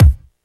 Kick OS 04.wav